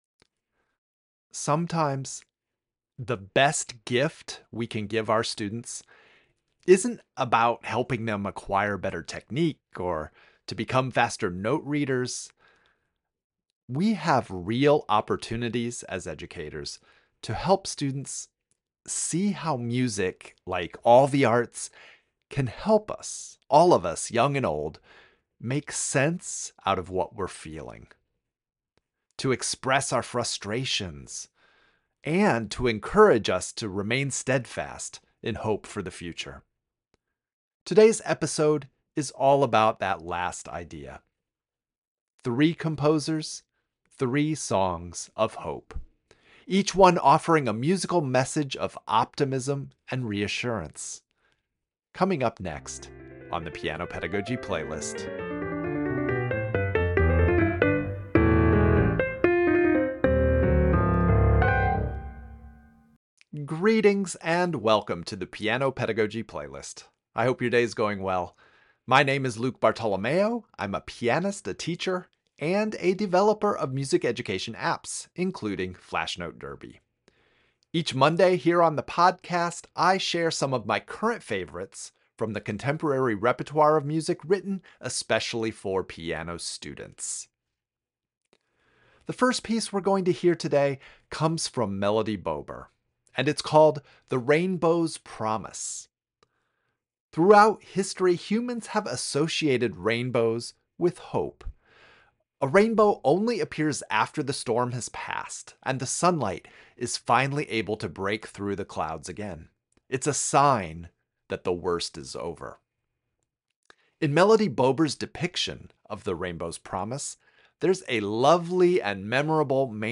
The week features music that offers reassurance, optimism, and a hopeful outlook for the future. I'm playing three pieces by three different composers, ranging from early intermediate through early advanced — each selection offering meaningful musical expression alongside valuable teaching opportunities.
• Chanson d’espoir by Mary Leaf — an early advanced work whose emotional journey from C minor to C major reminds us that hope is sometimes a conscious and determined choice.